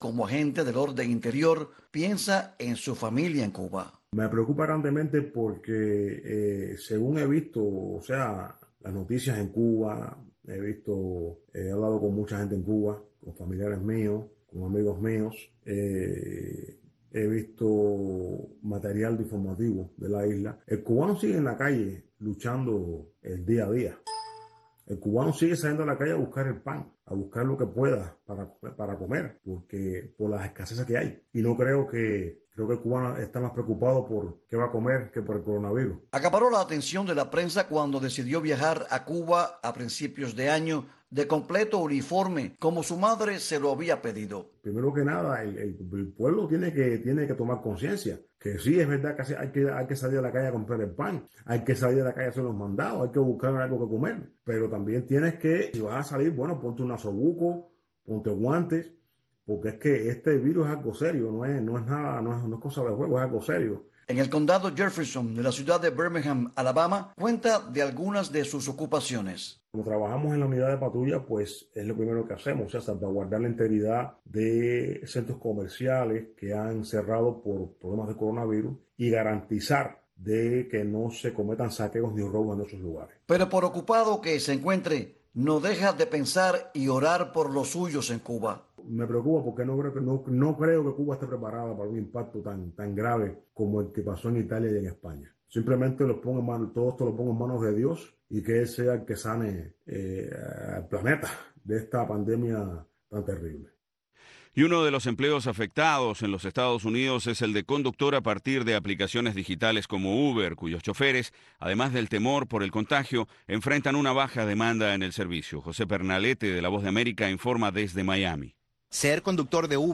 Programa humanista, preservador de buenas costumbres, reflexivo, aderezado con música y entrevistas. Las artes, el deporte, la ciencia, la política, e infinidad de tópicos, caben en este programa que está diseñado para enaltecer nuestras raíces, y para rendir tributo a esa bendita palabra que es Familia.